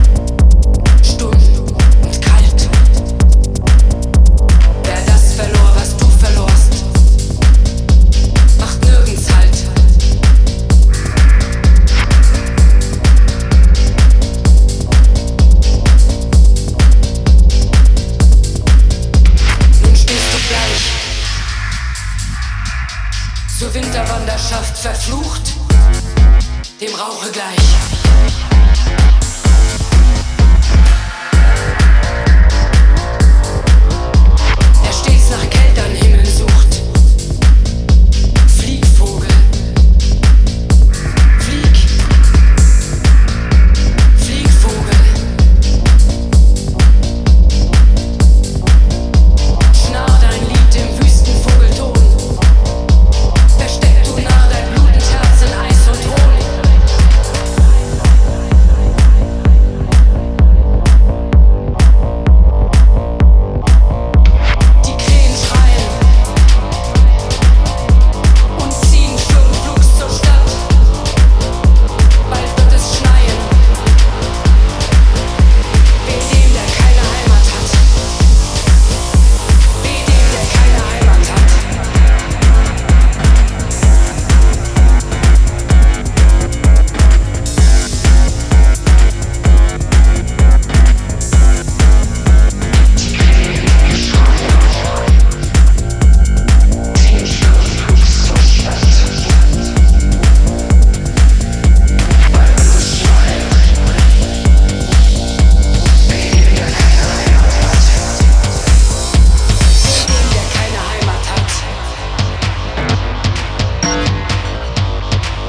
enigmatic belgian Techno Body Music duo
Electro Techno Wave